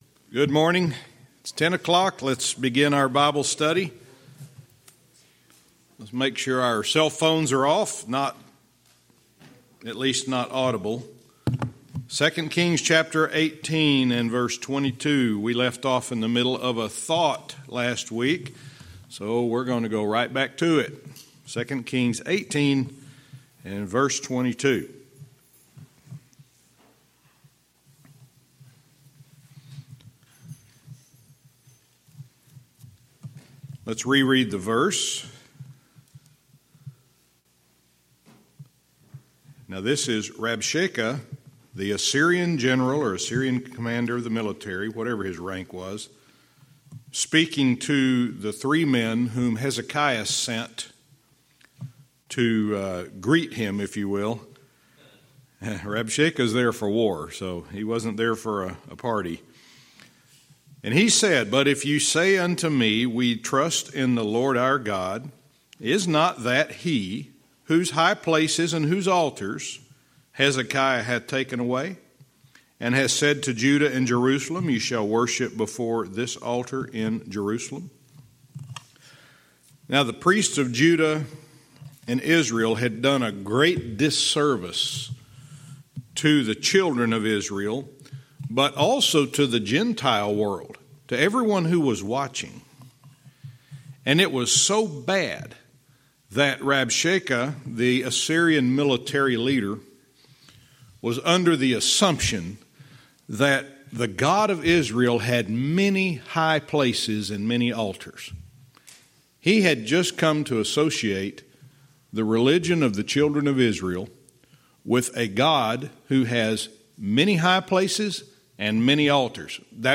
Verse by verse teaching - 2 Kings 18:22-24